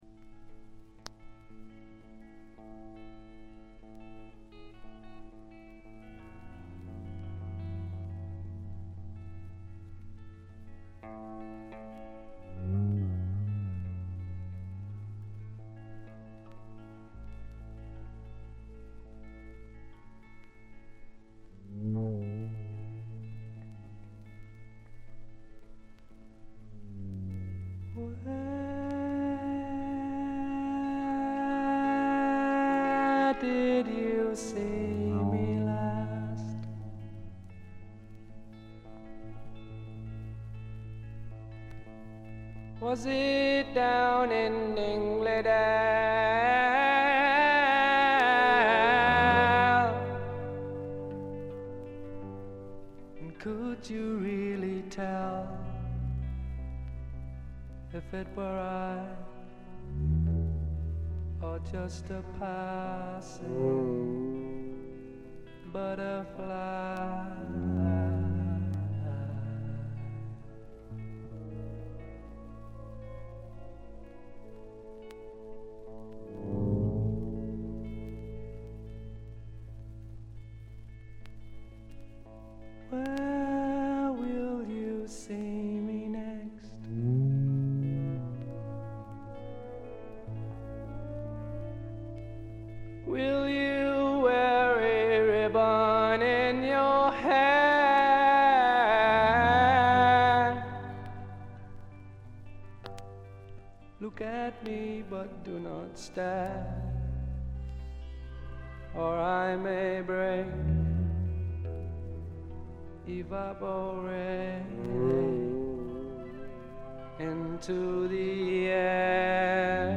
静音部でのチリプチ（特にB6）程度。
これぞ英国流フォークロックとも言うべき名作です。
試聴曲は現品からの取り込み音源です。